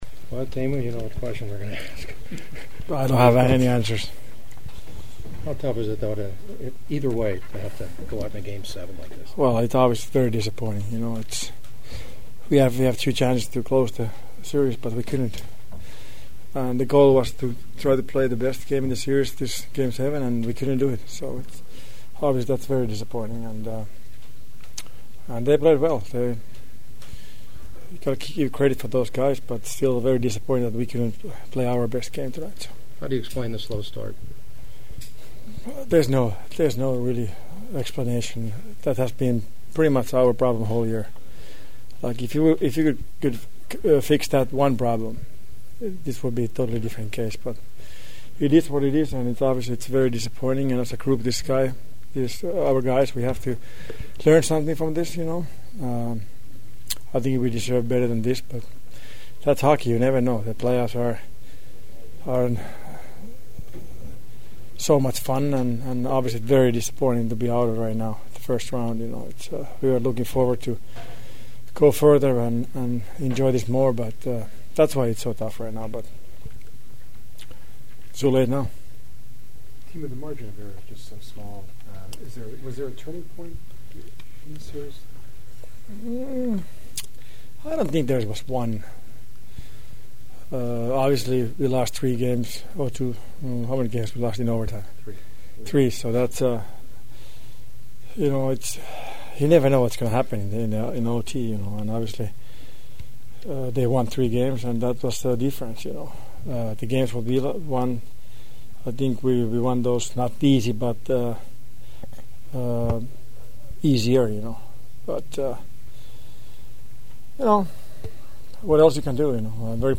The Ducks had an NHL best 15 come from behind wins during the regular season but their magic also ran out something that was one of my themes of questioning in the postgame locker room.
And finally Teemu Selanne who I believe him when he says that he needs time to think about his future plans (and NO, this isn’t like the Dwight Howard situation-not even close!):